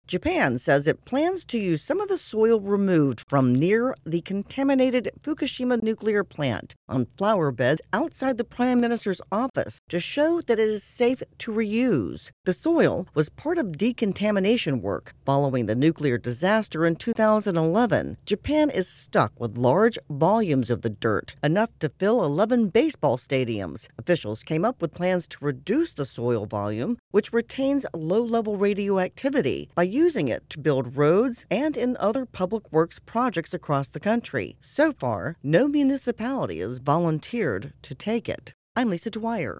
reports on an effort to ease fears about contaminated soil in Japan.